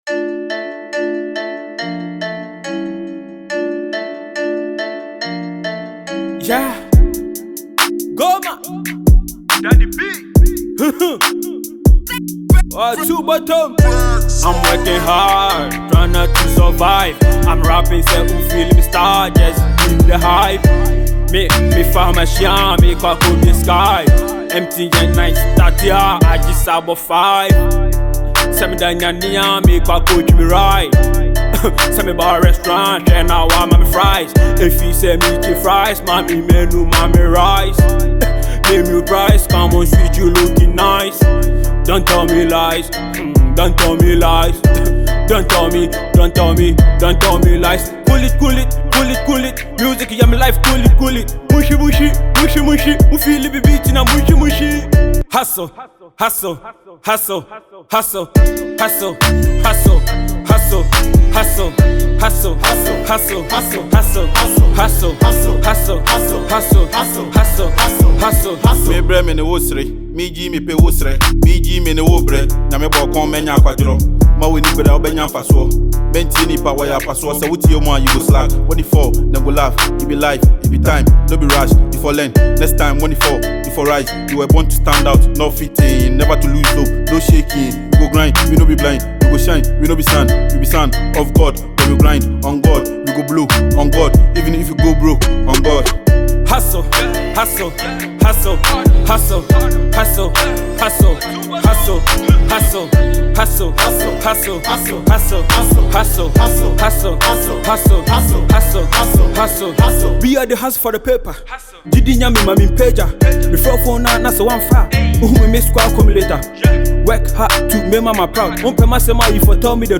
Fast-rising Ghanaian rapper cum singer
trap music
featured colleague rapper